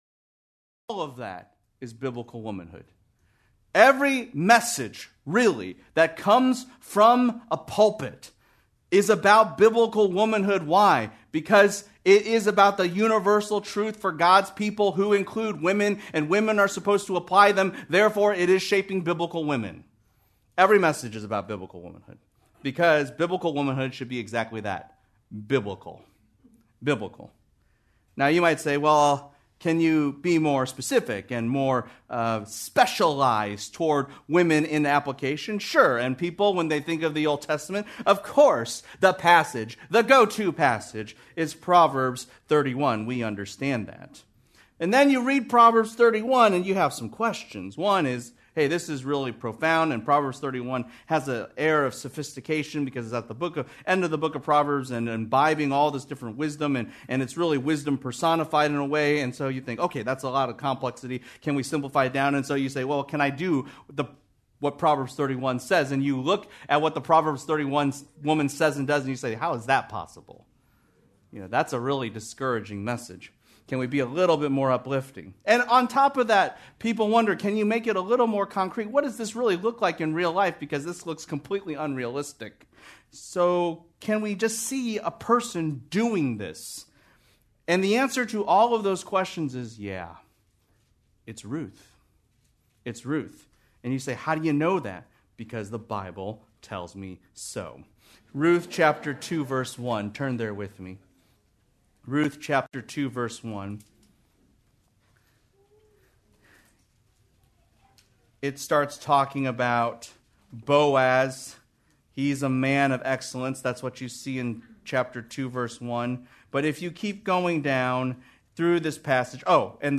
Due to technical difficulties this sermon is incomplete.